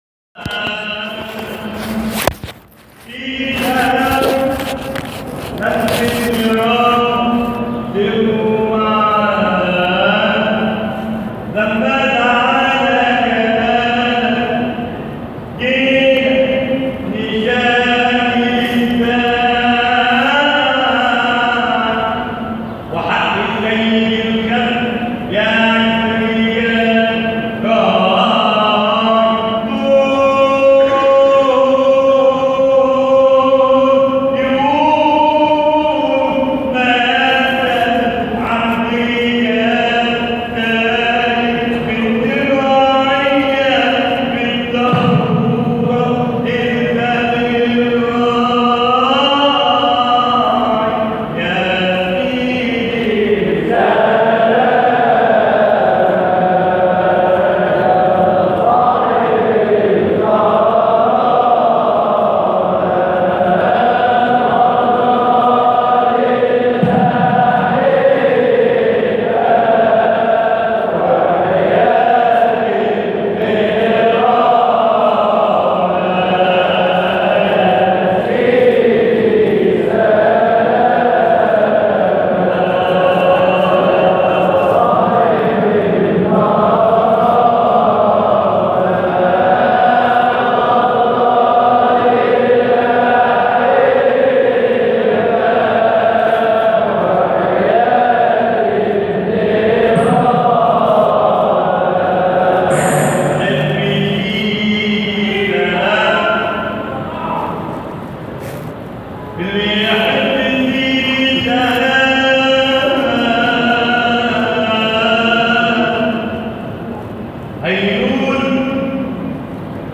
مقاطع من احتفالات ابناء الطريقة الحامدية الشاذلية بمناسباتهم
حلقة ذكر كاملة بمدينة المنصورة يوم 9 رمضان 1439 هـ – يناير 2018